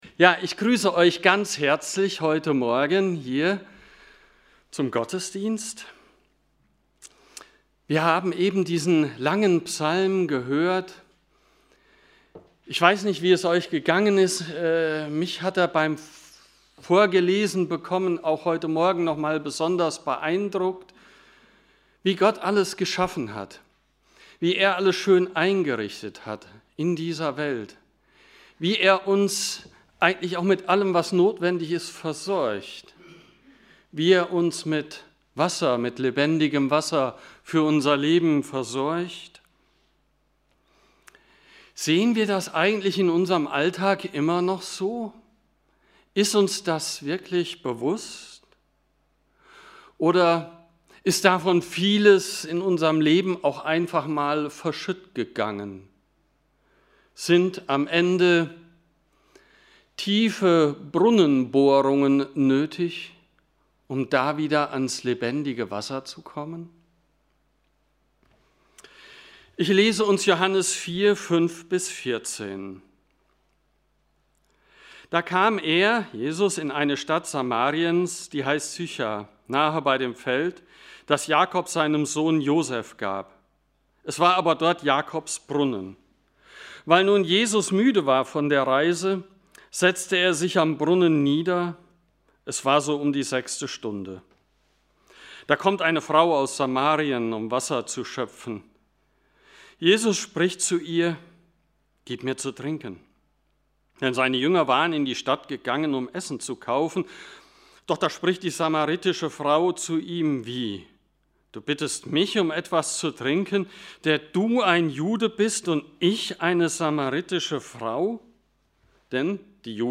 Lebensdurst ~ Predigt Podcast Evangelische Gemeinschaft Kredenbach Podcast
Predigten der Evangelischen Gemeinschaft Kredenbach.